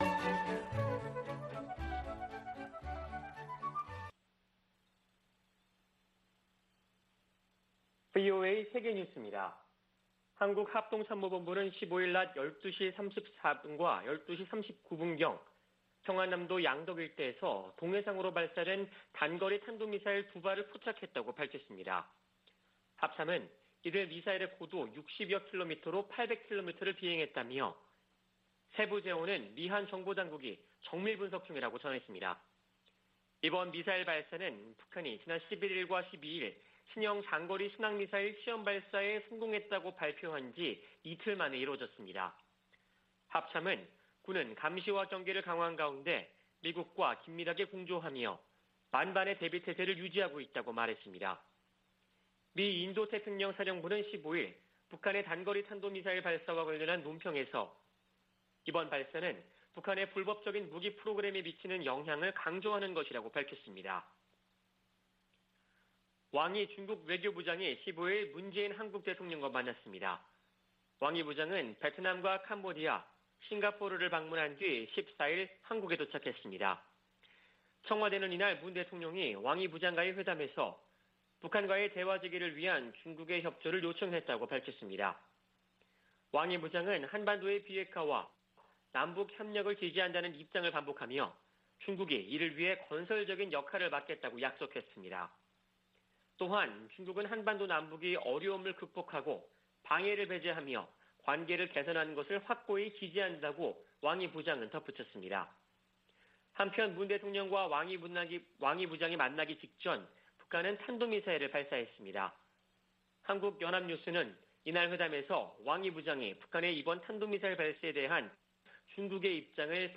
VOA 한국어 아침 뉴스 프로그램 '워싱턴 뉴스 광장' 2021년 9월 16일 방송입니다. 북한이 15일 동해로 단거리 탄도미사일 두 발을 발사했습니다. 미국은 북한의 이번 발사가 불법적 무기 프로그램에 따른 불안정을 강조했다고 밝혔고, 일본은 유엔 안보리 결의 위반임을 강조했습니다.